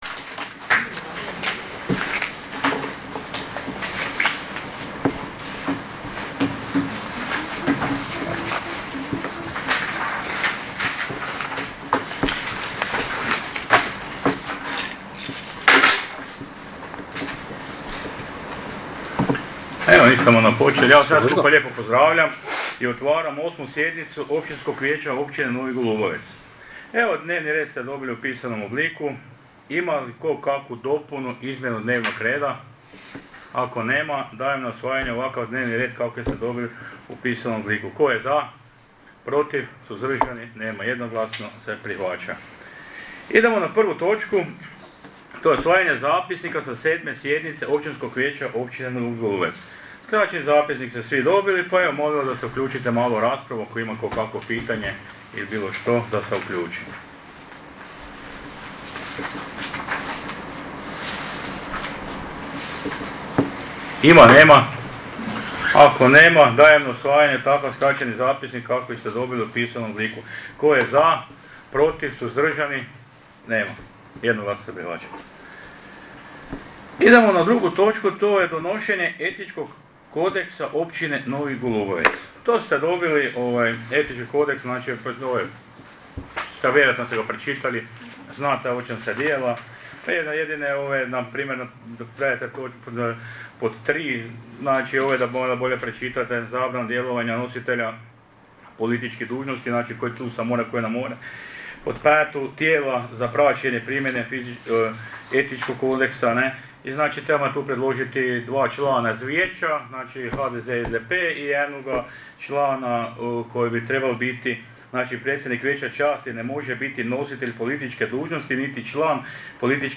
8 sjednica općinskog vijeća.MP3